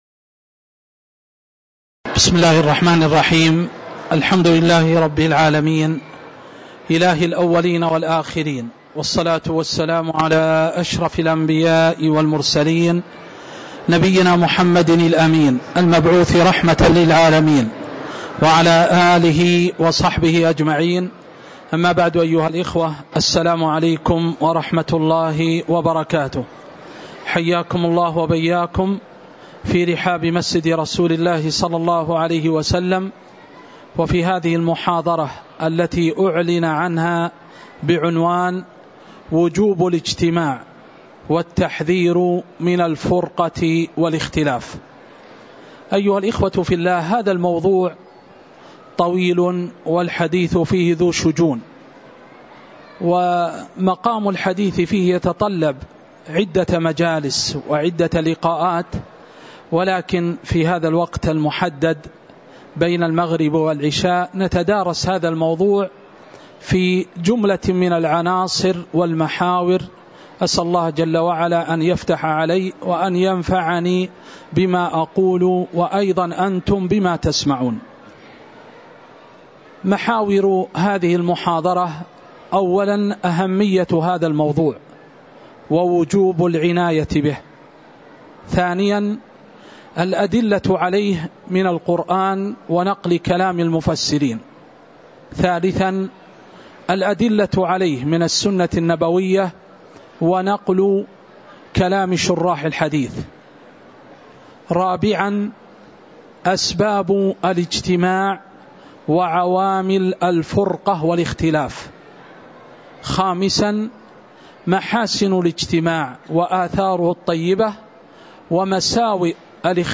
تاريخ النشر ٢٧ جمادى الآخرة ١٤٤٥ هـ المكان: المسجد النبوي الشيخ